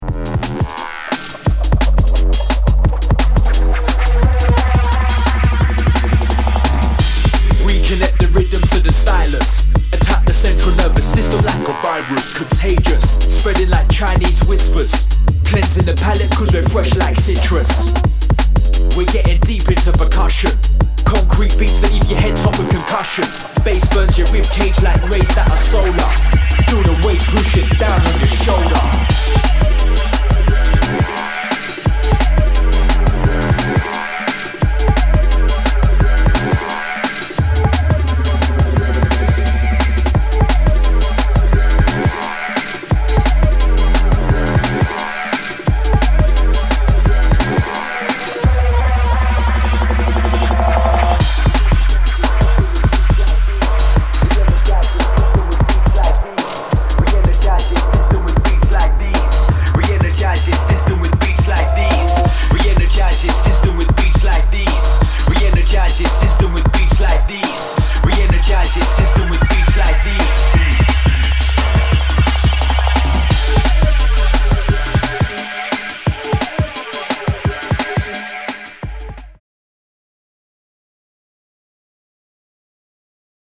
Jungle/Drum n Bass